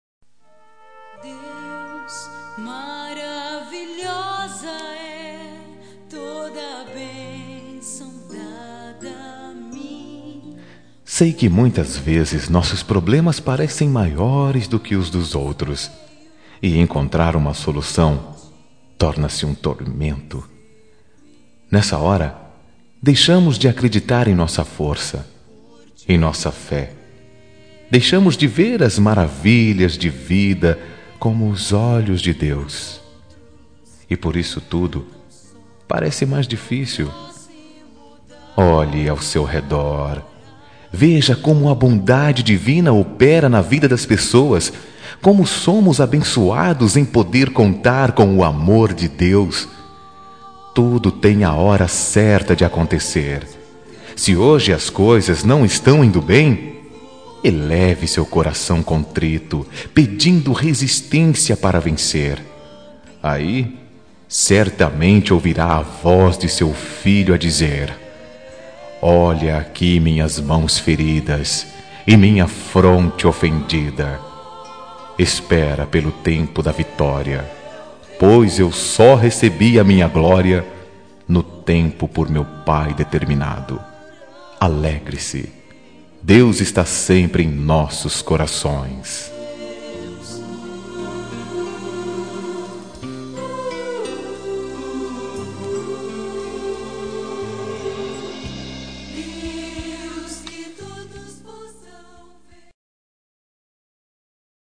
Telemensagem de Otimismo – Voz Masculina – Cód: 183